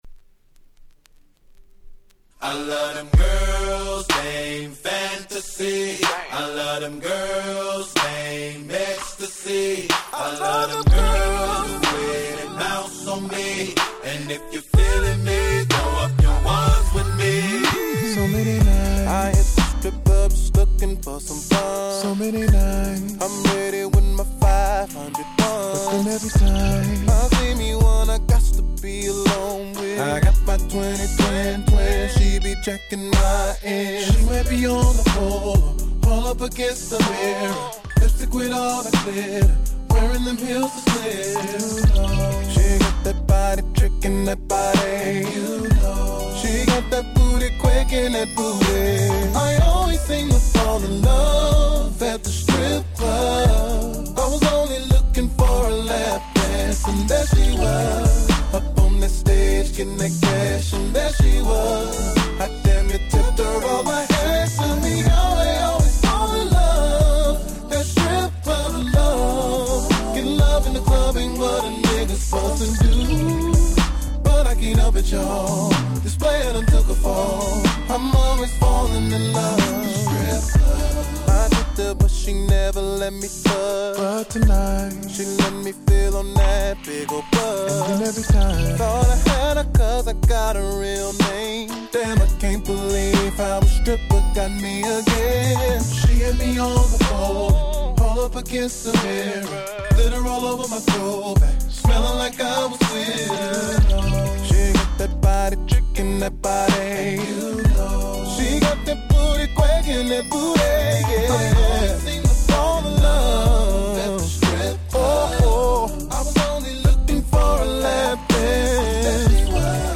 05' Nice Slow Jam !!